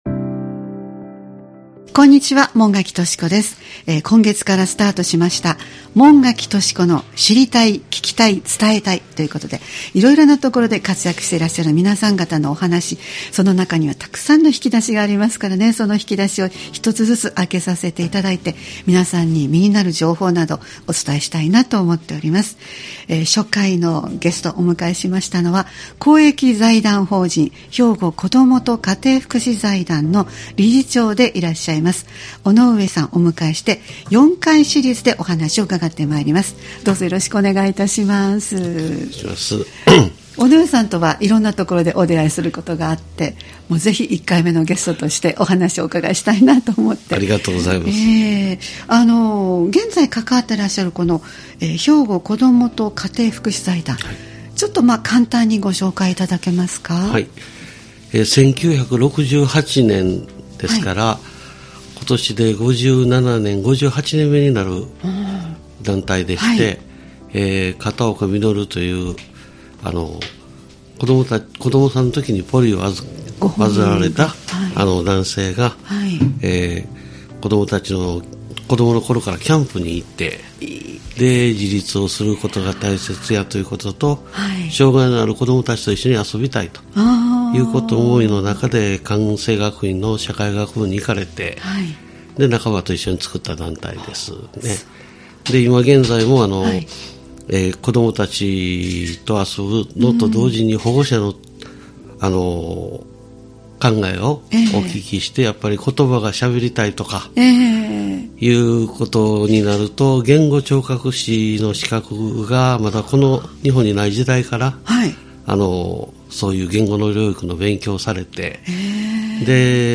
この番組では、各方面で活躍されている方にスポットを当て、これまでどのような出会いがあったのか、また当時思ったことや感じたこと、そして今取り組んでいることや伝えたいことなどをお聞きしていきます。